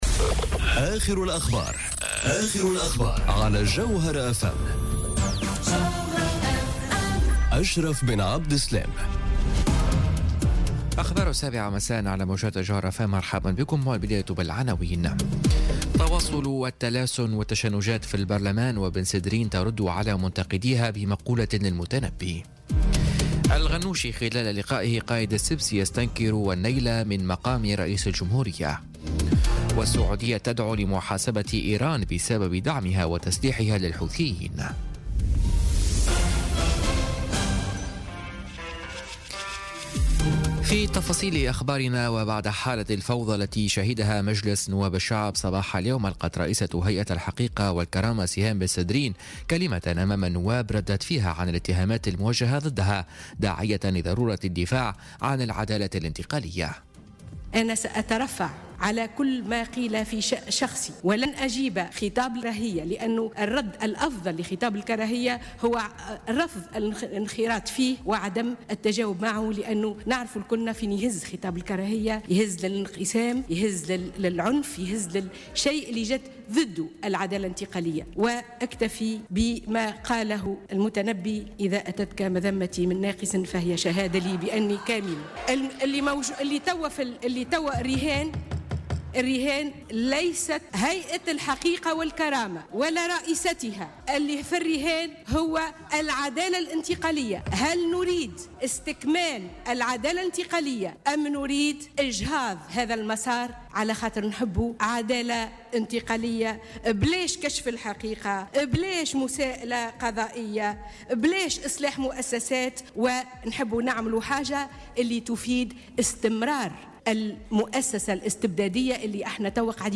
Journal Info 19h00 du lundi 26 Mars 2018